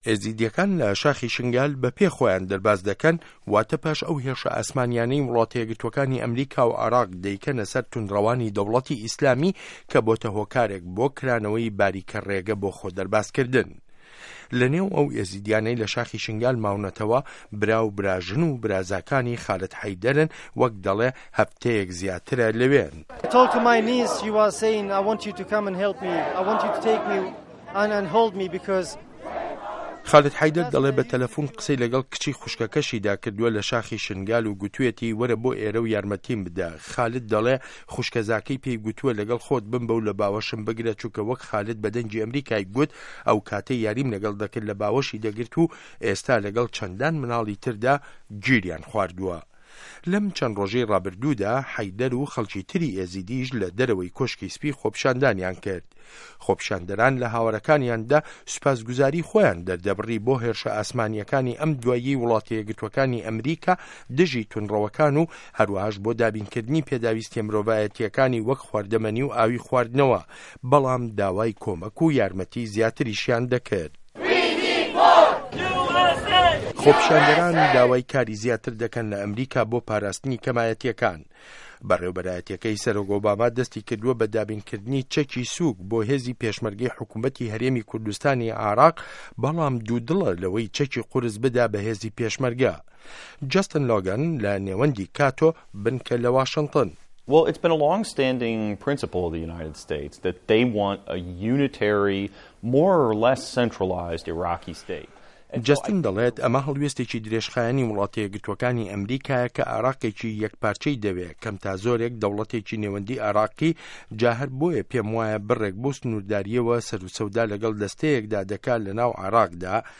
ڕاپۆرتی ئێزیدیه‌کان داوای پاراستنی که‌مایه‌تیه‌کان ده‌که‌ن له‌ ئه‌مریکا